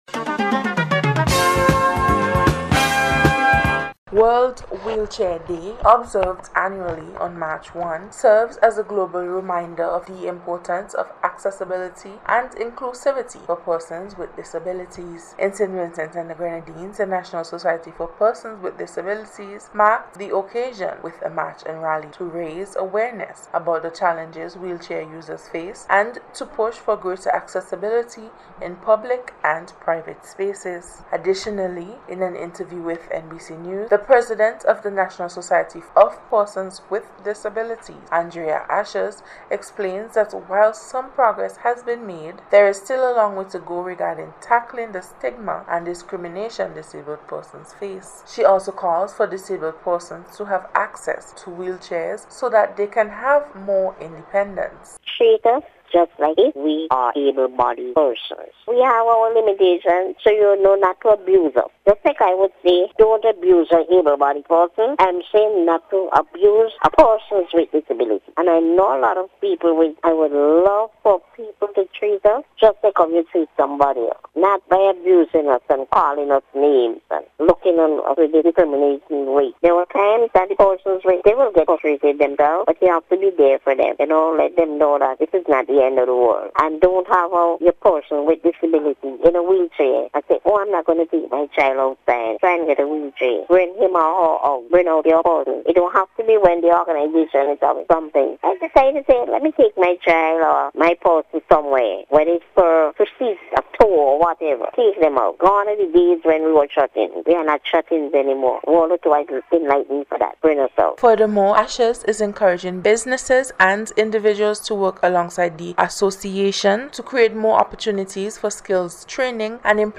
WHEELCHAIR-ACCESS-AND-INCLUSION-REPORT.mp3